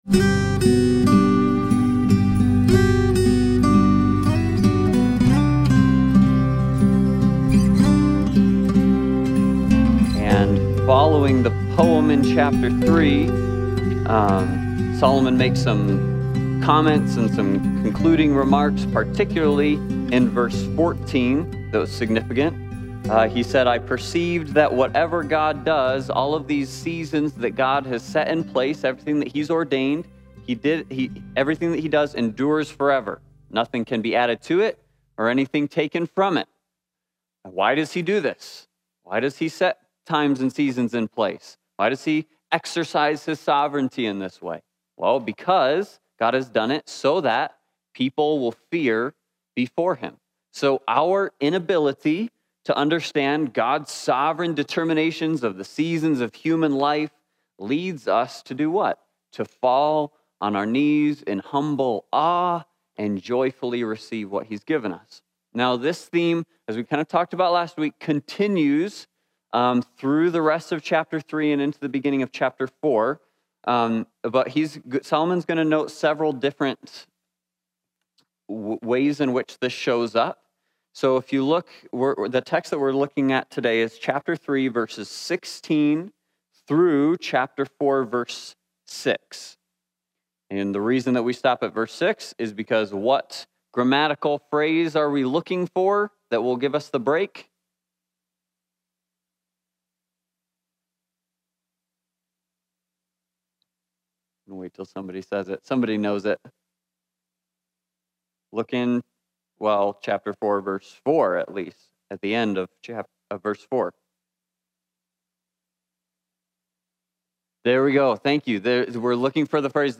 Passage: Ecclesiastes 3:16-4:4 Service Type: Sunday Bible Study